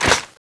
Index of /server/sound/weapons/tfa_cso/m249ep
shoot3.wav